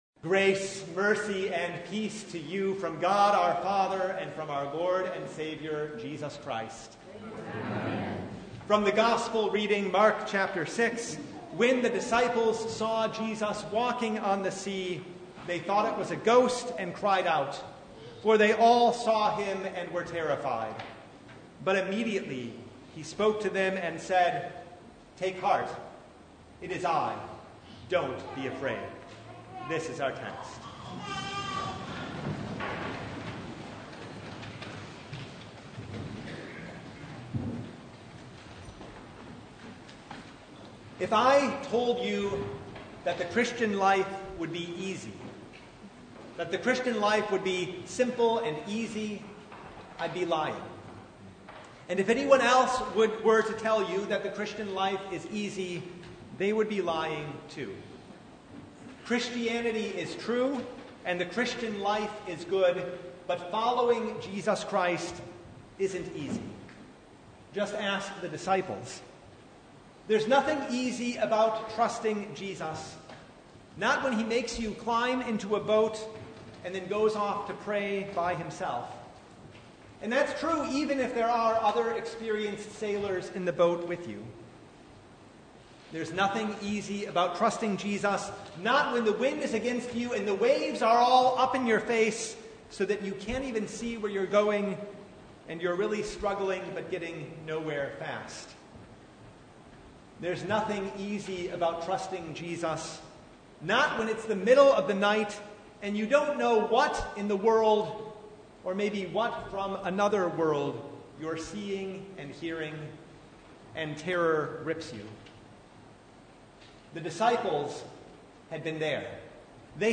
Mark 6:45-56 Service Type: Sunday It isn’t easy to trust Jesus